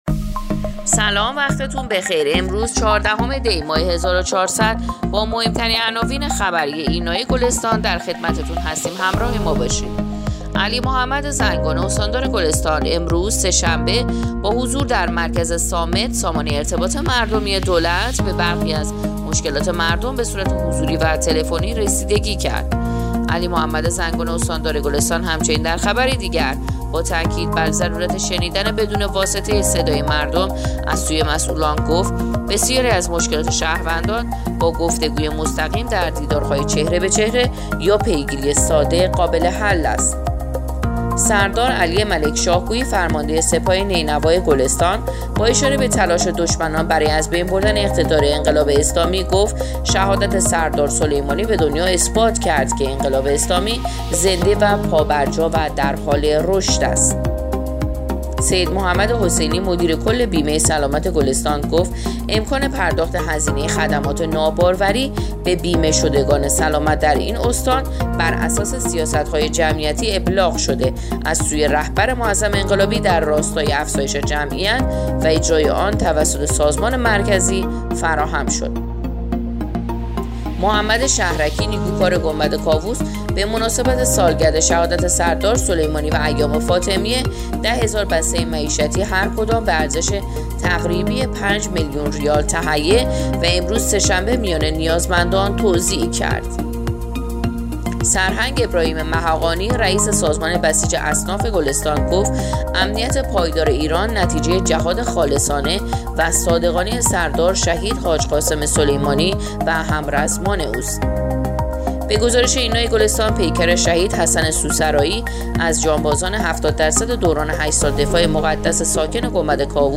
پادکست/ اخبار شبانگاهی چهاردهم دی ماه ایرنا گلستان